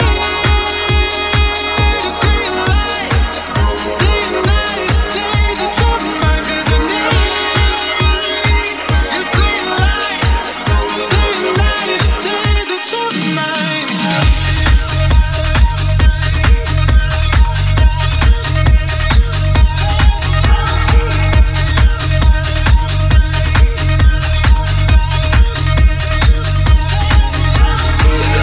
deep house
Genere: house, deep house, remix